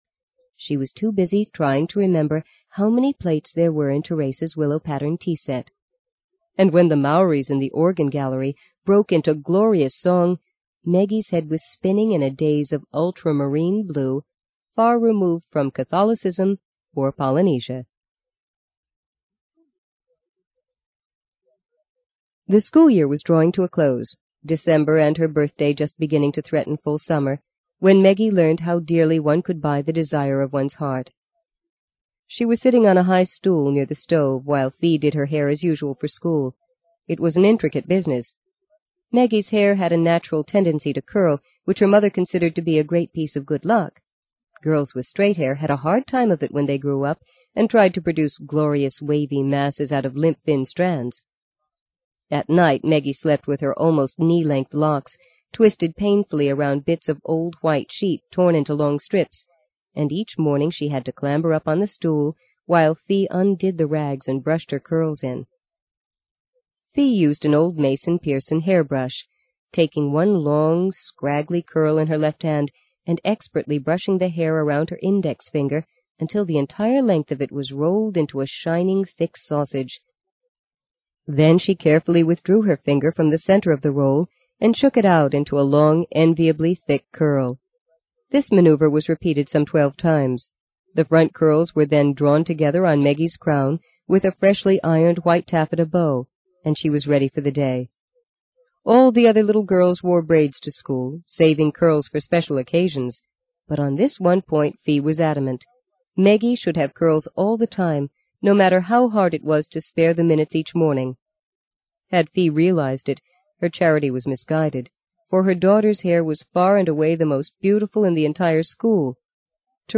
在线英语听力室【荆棘鸟】第二章 18的听力文件下载,荆棘鸟—双语有声读物—听力教程—英语听力—在线英语听力室